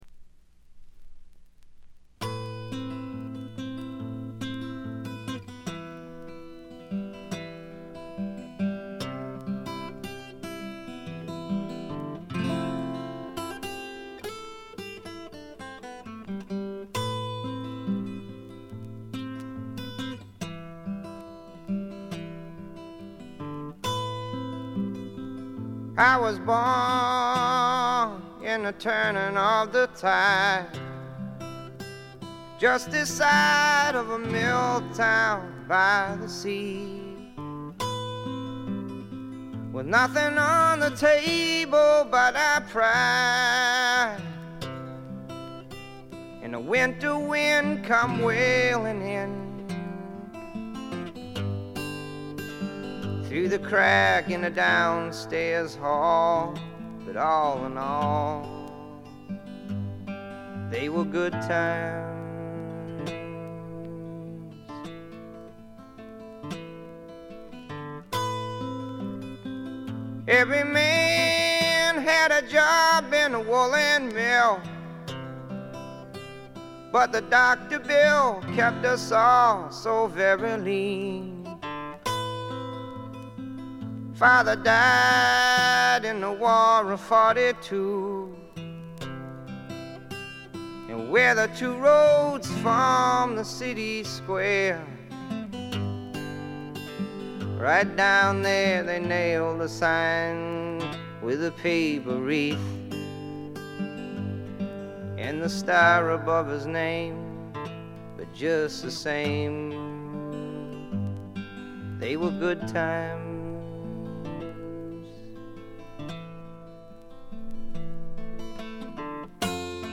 ごくわずかなチリプチ程度。
試聴曲は現品からの取り込み音源です。
Vocals, Guitar, Harmonica
Dobro, Guitar
Keyboards
Bass
Drums